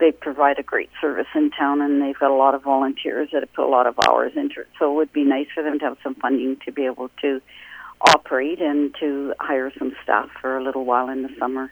Mayor Hamling feels it’s going to good use.